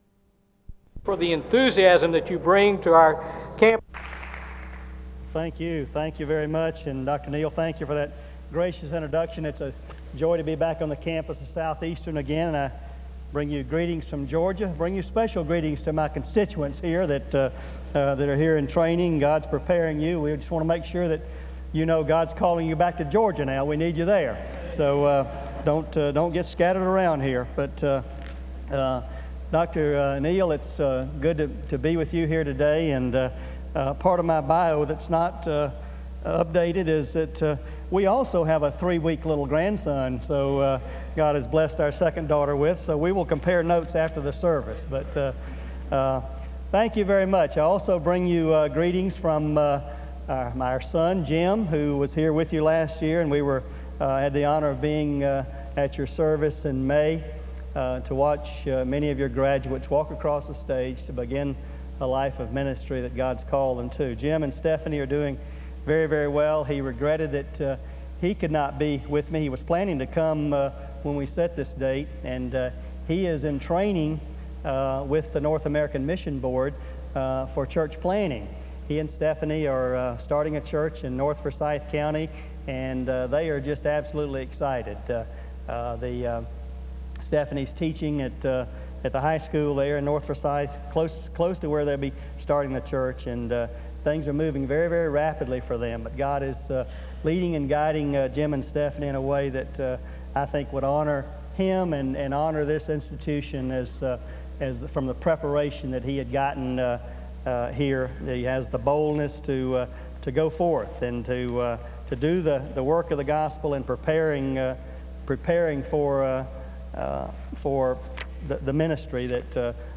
SEBTS Chapel - Sonny Perdue August 20, 2003
In Collection: SEBTS Chapel and Special Event Recordings - 2000s Thumbnail Titolo Data caricata Visibilità Azioni SEBTS_Chapel_Sonny_Perdue_2003-08-20.wav 2026-02-12 Scaricare